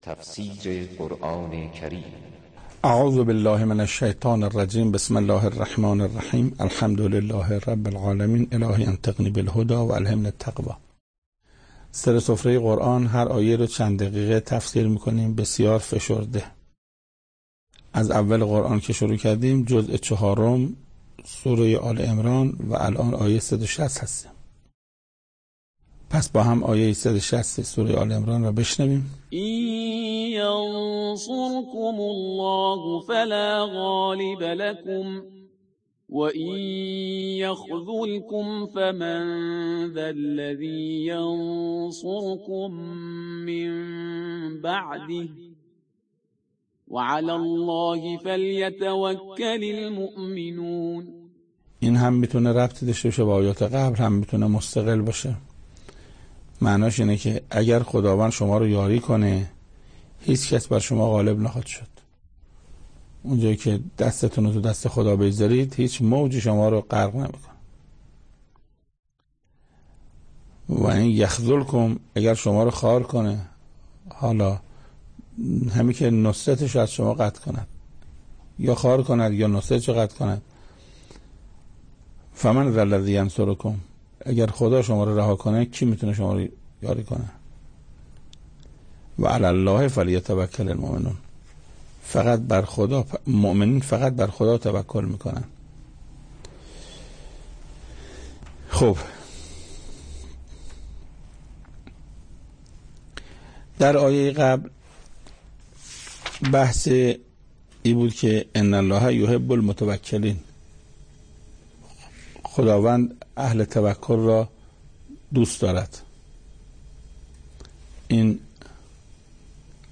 تفسیر آیه 160 سوره آل عمران - استاد محسن قرائتی در این بخش از ضیاءالصالحین، صوت تفسیر آیه صد و شصتم سوره مبارکه آل عمران را در کلام حجت الاسلام استاد محسن قرائتی به مدت 7 دقیقه با شما قرآن دوستان عزیز به اشتراک می گذاریم.